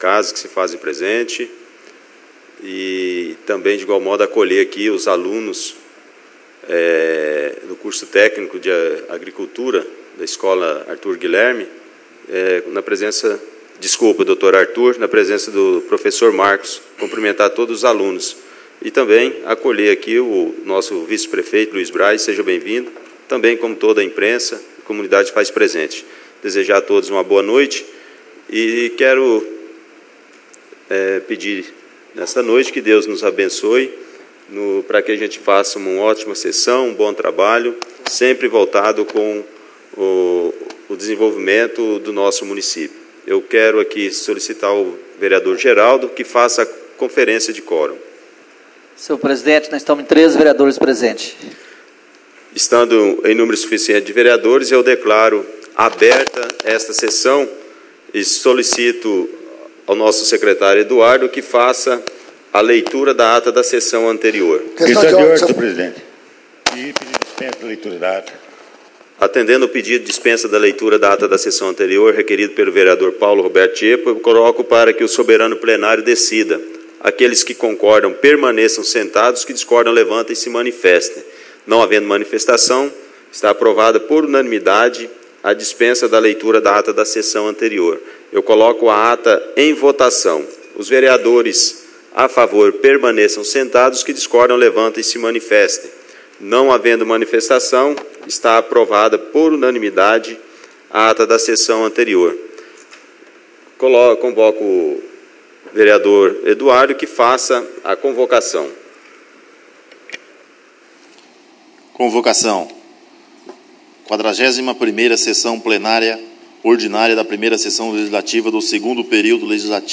Áudio na íntegra da Sessão Ordinária realizada no dia 27/11/2017 as 20 horas no Plenário Henrique Simionatto.